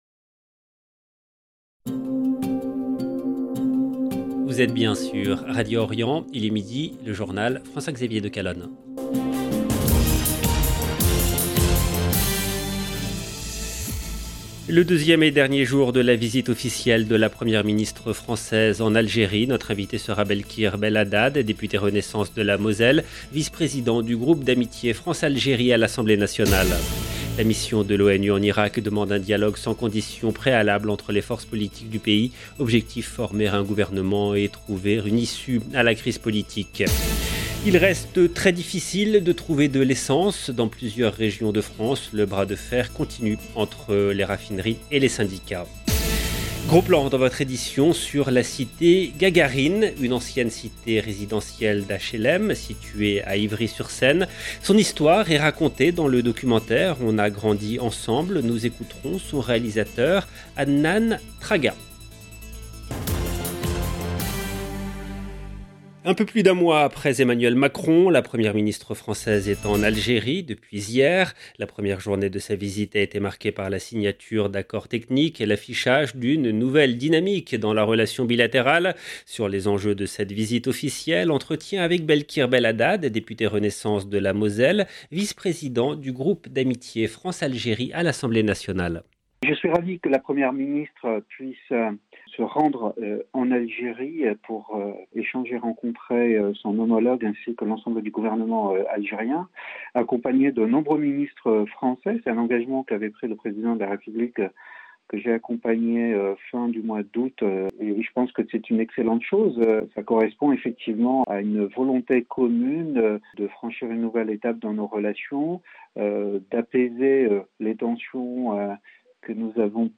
Le deuxième et dernier jour de la visite officielle de la Première ministre française en Algérie. Notre invité sera Belkhir Belhaddad, député Renaissance de Moselle, vice président du groupe d’amitié France-Algérie à l’Assemblée nationale.